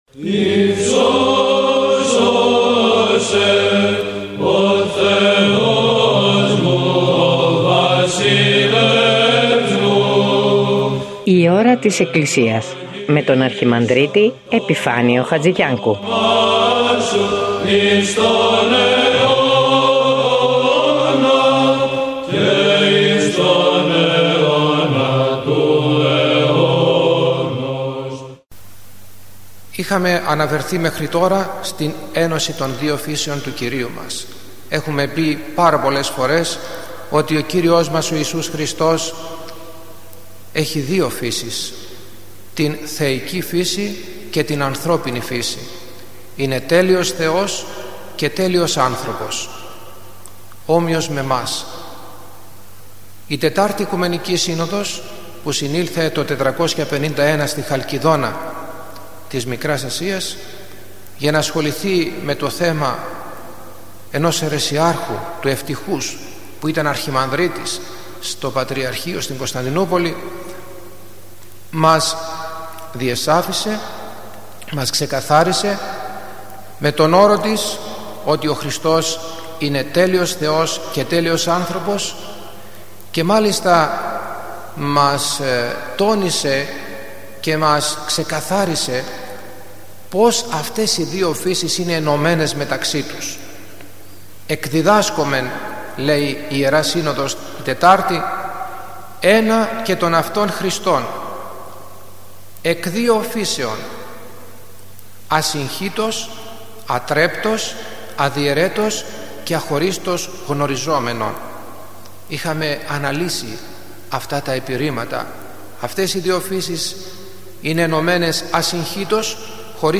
Μια ένθετη εκπομπή που μεταδίδονται ομιλίες
Η παρούσα ομιλία έχει θεματολογία «Περί της Θεοτόκου».